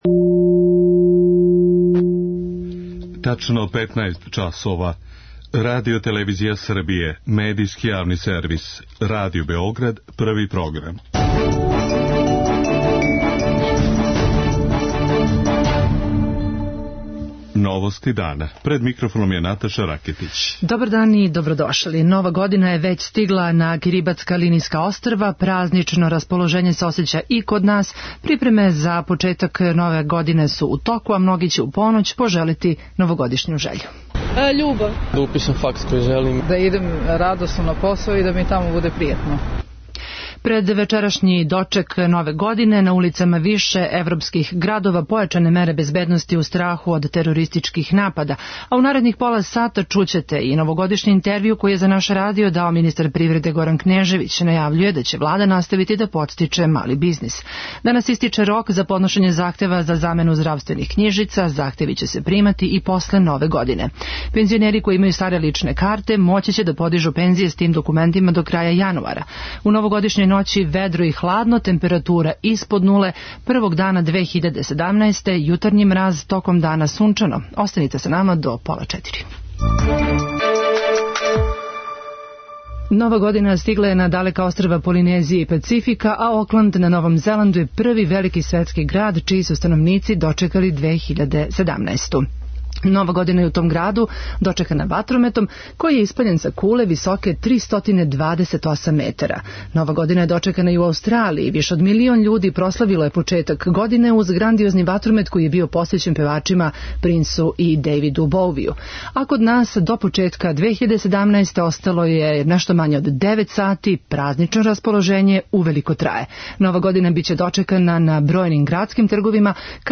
Чућете и новогодишњи интервју који је за наш радио дао министар привреде Горан Кнежевић.